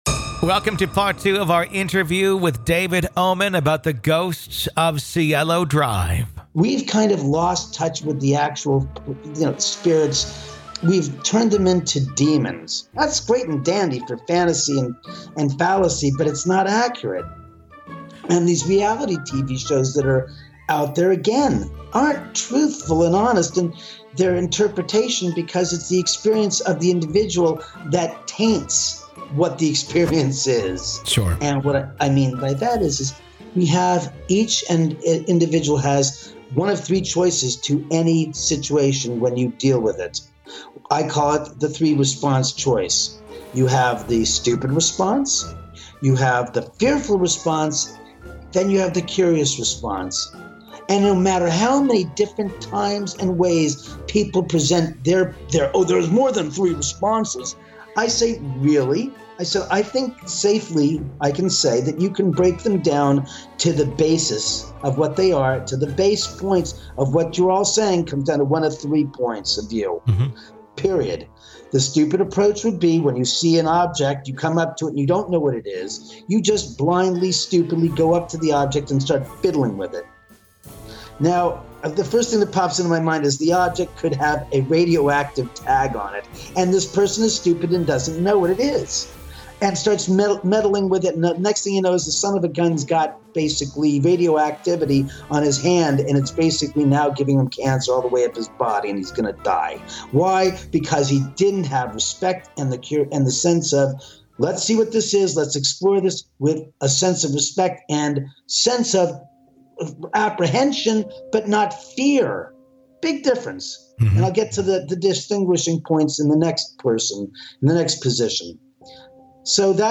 This is Part Two of our conversation.